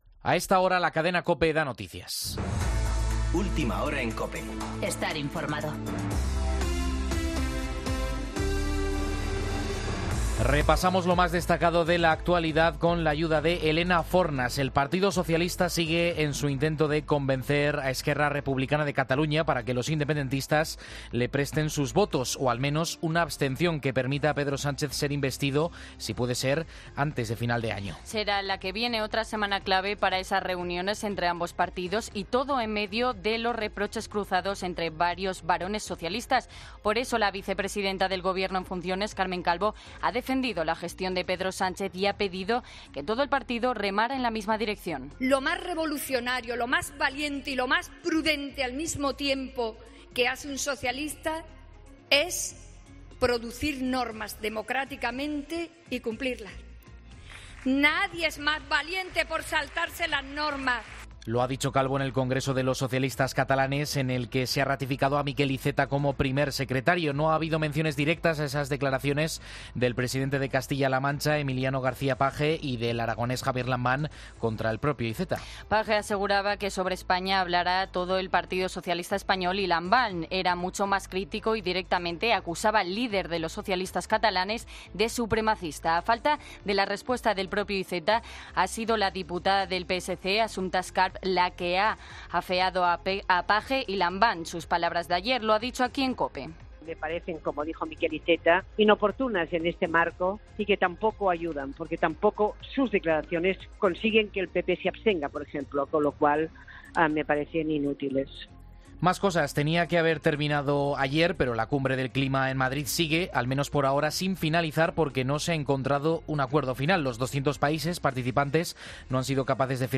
Boletín de noticias COPE del 14 de diciembre de 2019 a las 17.00 horas